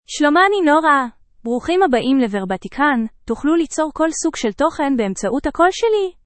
NoraFemale Hebrew AI voice
Nora is a female AI voice for Hebrew (Israel).
Voice sample
Listen to Nora's female Hebrew voice.
Nora delivers clear pronunciation with authentic Israel Hebrew intonation, making your content sound professionally produced.